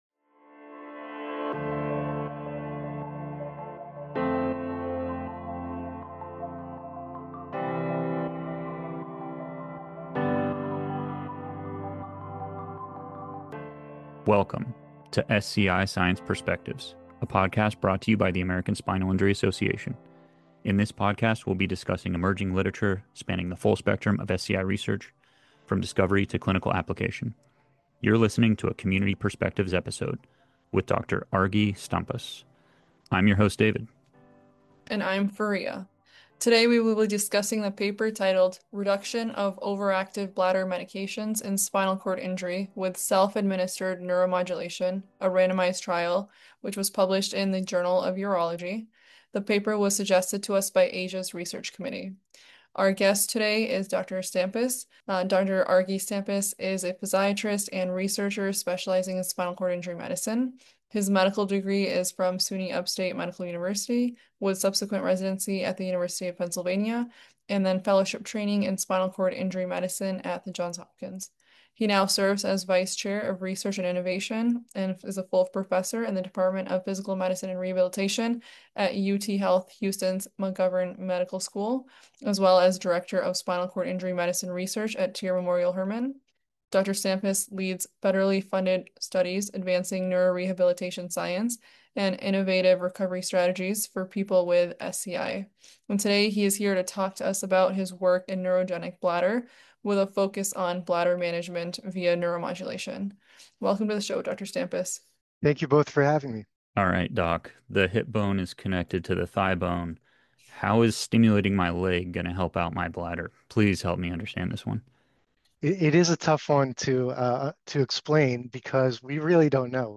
The podcast is built around two-part dialogues with spinal cord injury (SCI) professionals regarding their emerging scientific work spanning the full spectrum of SCI research, from discovery to clinical application.
Then the podcast host(s) then interview the author(s) of the papers, approaching their project from each perspective.